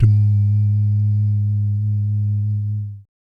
Index of /90_sSampleCDs/ILIO - Vocal Planet VOL-3 - Jazz & FX/Partition B/3 BASS DUMS